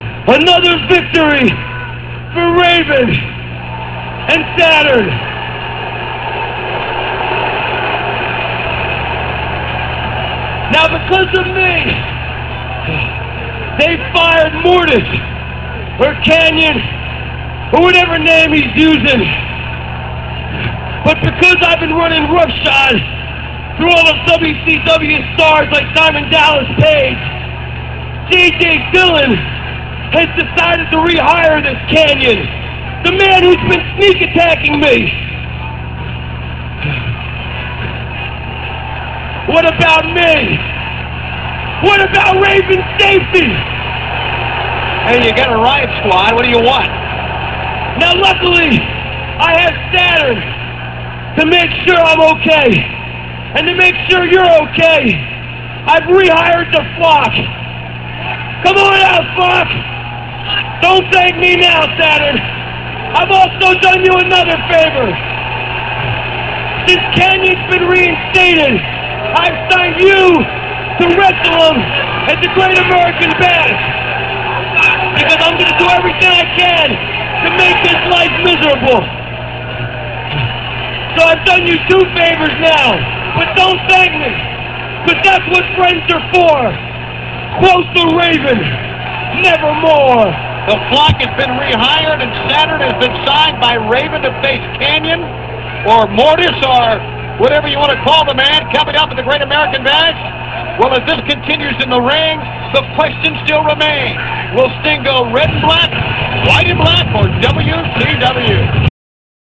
- This comes from WCW Nitro - [06.01.98]. Raven says he's rehiring the Flock for Saturn's own safety as a sign of friendship.